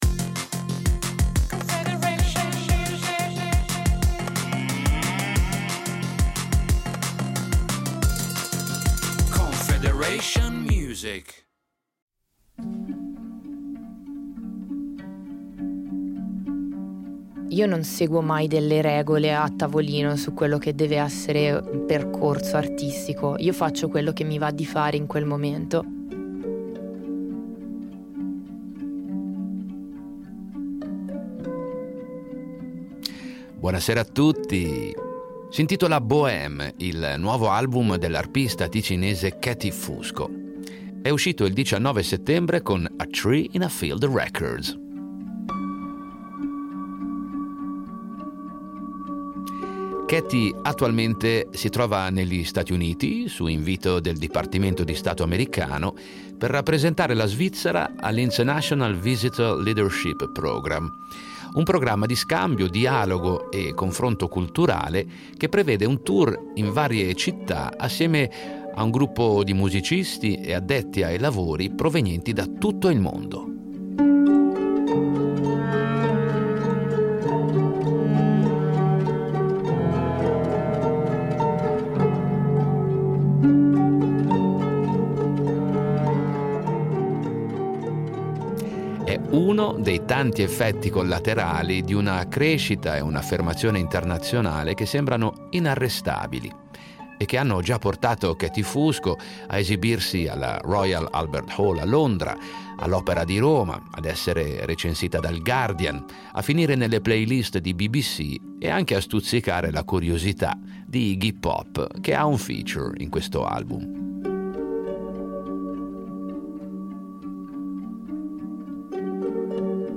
Musica elettronica